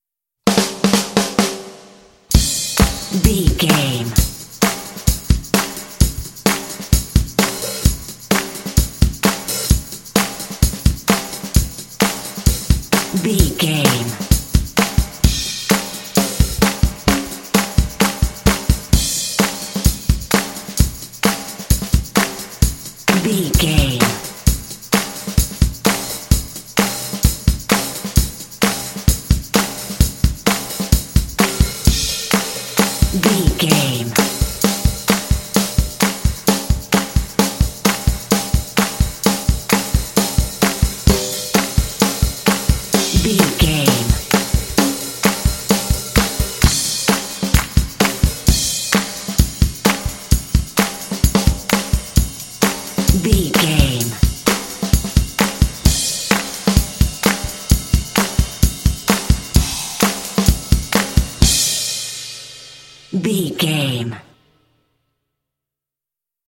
Fast paced
Atonal
confident
energetic
rock
heavy metal
classic rock